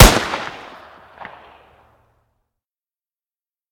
pistol_fire.ogg